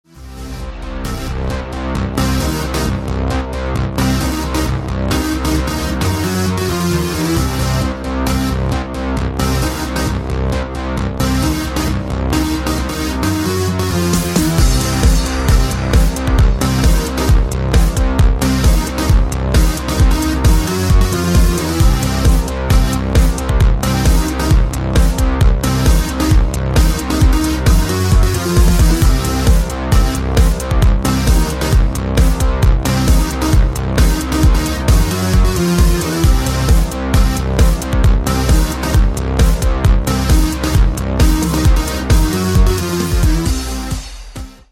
• Качество: 128, Stereo
громкие
dance
Electronic
без слов
Tech House
electro house